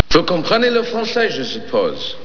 To top it off and go totally over the top, here's three quotes from his stand-up routines, from The soon to be Infamous Eddie Pick-up Page, namely a bit of French, the quick aside and how to accept a yes subtly.